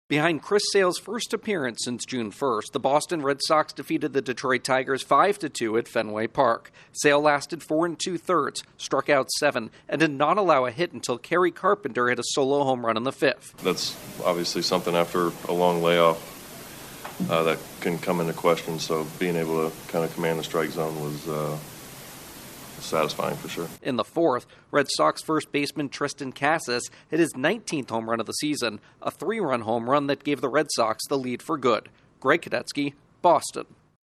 The Red Sox get a shot in the arm from a returning hurler. Correspondent